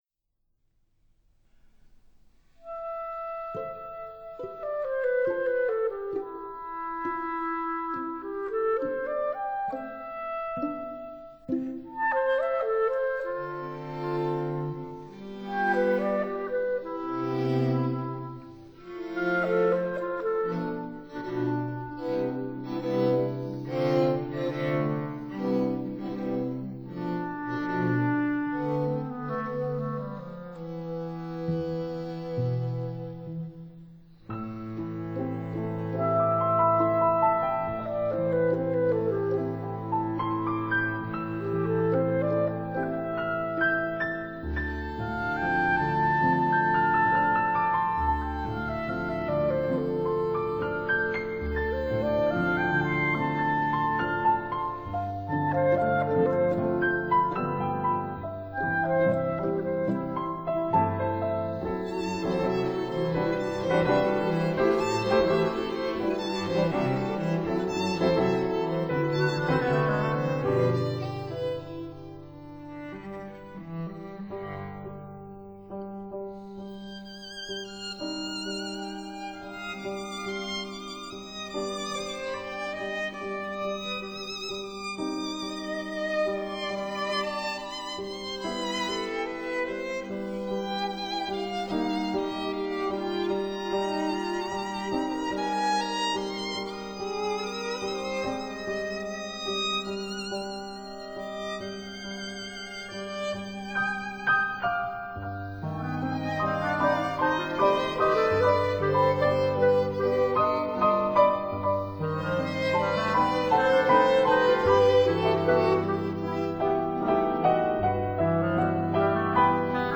clarinet
violin
cello
piano